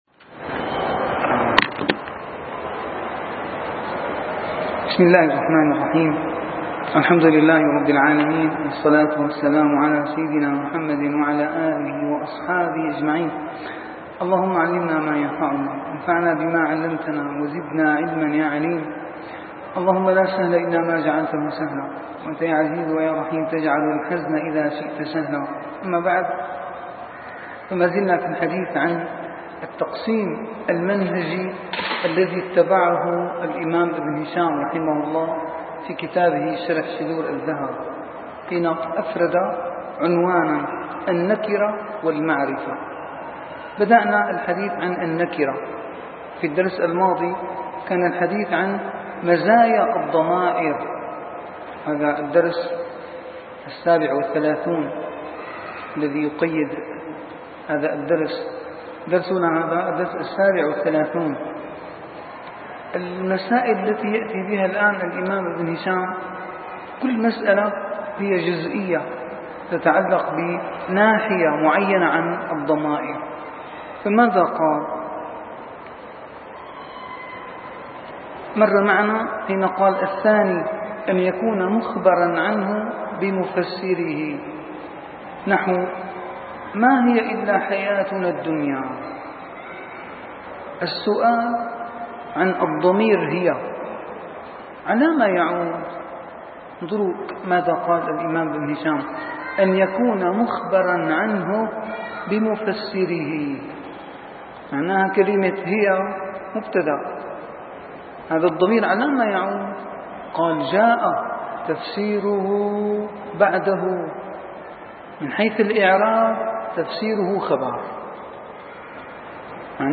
- الدروس العلمية - شرح كتاب شذور الذهب - 37- شرح كتاب شذور الذهب: تتمة الضمائر وأسماء الأعلام